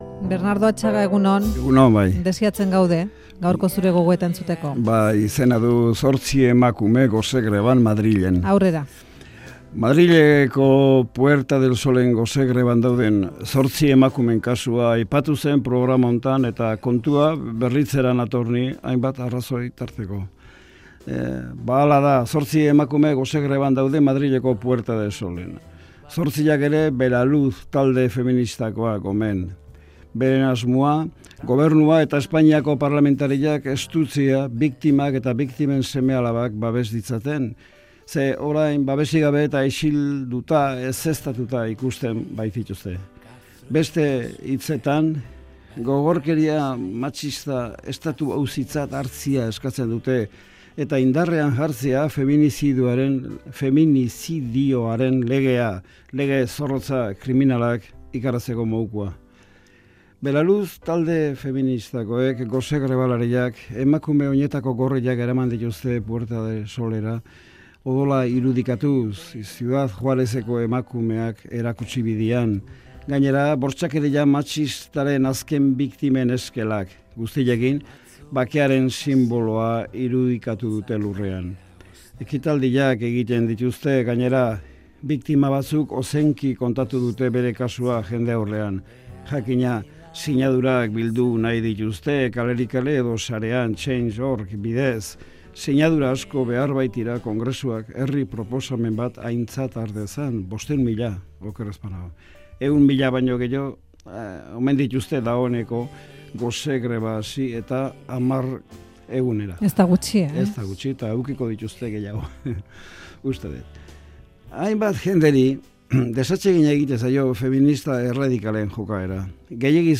Audioa: Gogorkeria matxista estatu-auzitzat har dezaten eskatzen duten emakumeen protestalditik abiatu da Bernardo Atxaga Euskadi Irratiko Faktorian